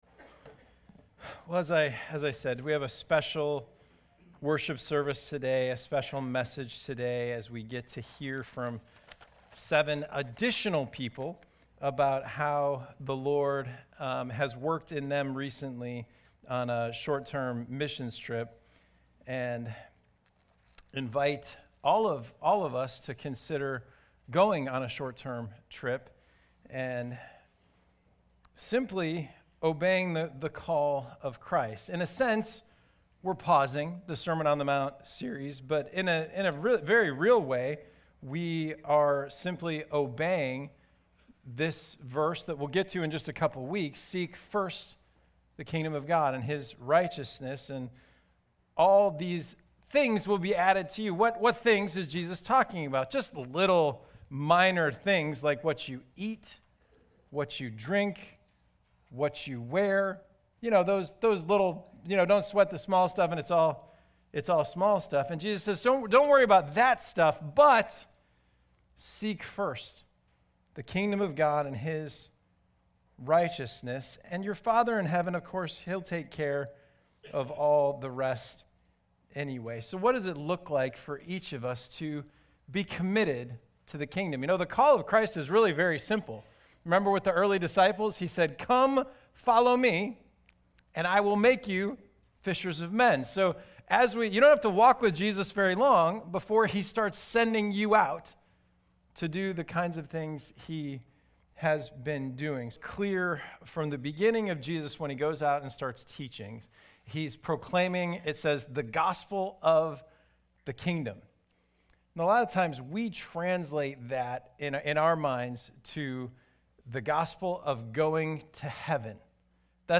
This is a special Sunday service as we will hear from a number of people who have recently been on a short-term missions trip and are excited to share how God is working in their lives as a result.